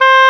WND OBOE C5.wav